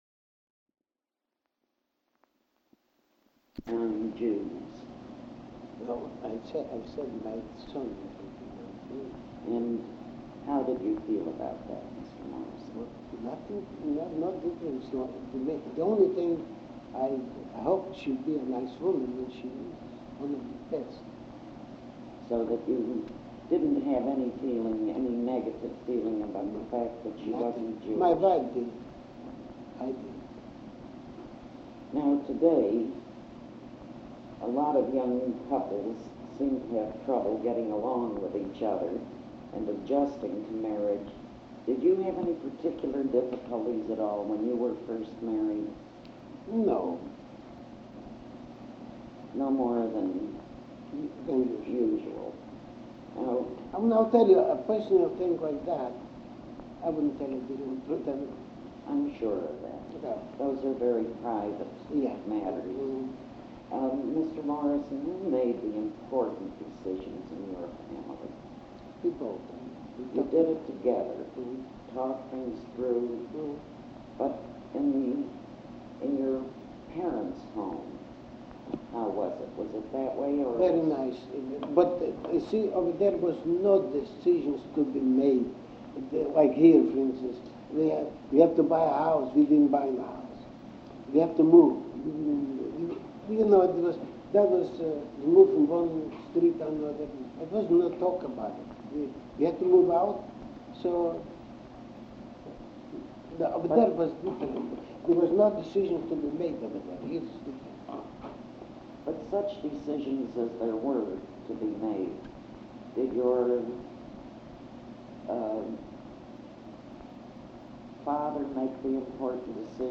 TAPE QUALITY POOR Provides biographical details.